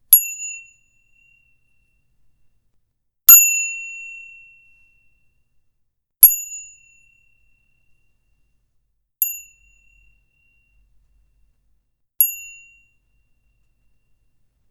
Five Dings
bell bicycle-bell ding high-pitched ping small table-bell ting sound effect free sound royalty free Sound Effects